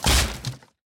Sound / Minecraft / mob / zombie / woodbreak.ogg
woodbreak.ogg